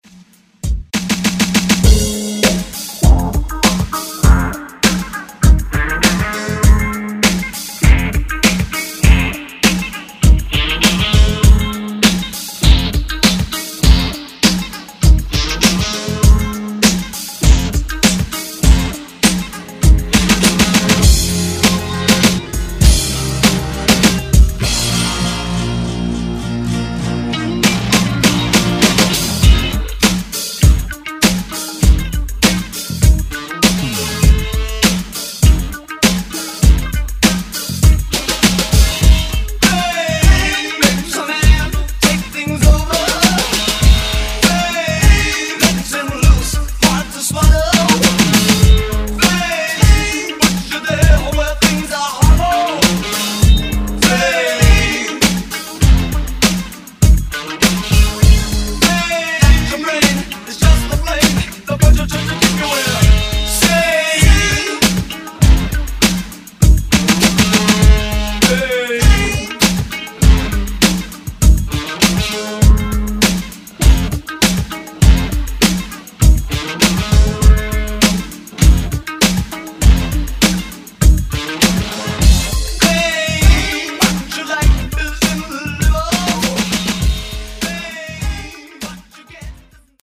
Genre: MASHUPS
Clean BPM: 128 Time